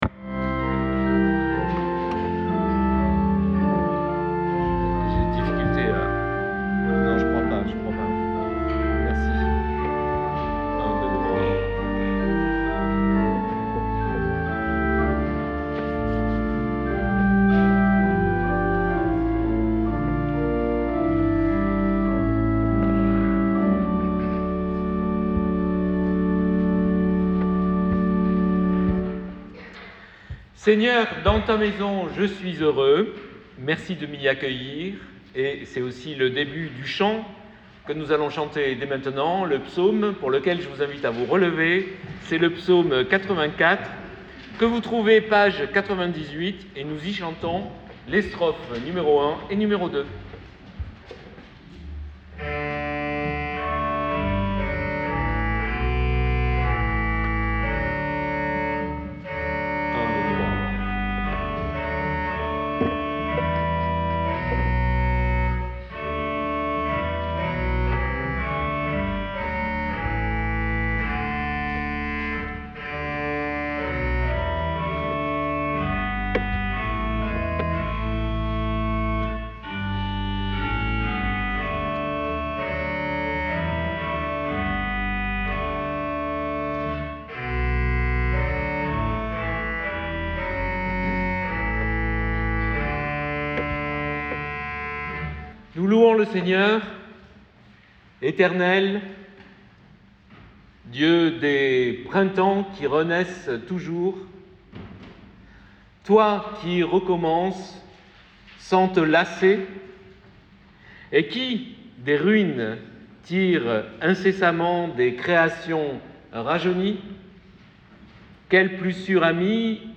LE CATÉCHISME DE L'EXTRAVAGANCE , culte du 19 janvier 2025